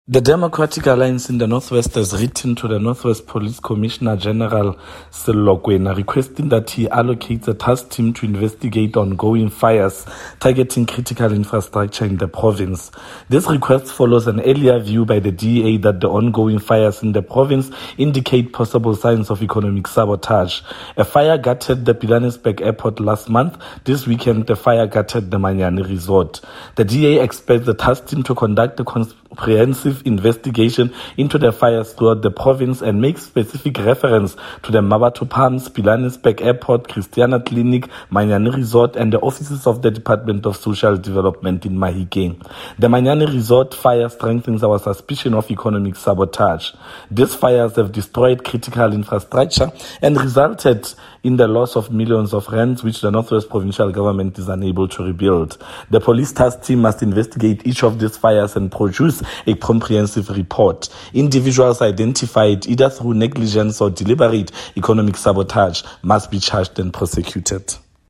Note to Broadcasters: Please find attached soundbite in